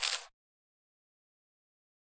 footsteps-single-outdoors-001-03.ogg